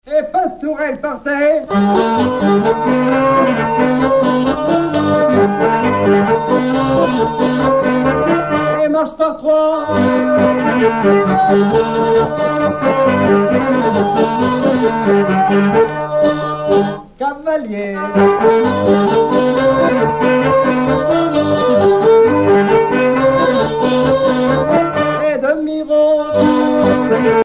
Chants brefs - A danser
danse : quadrille : pastourelle
Pièce musicale éditée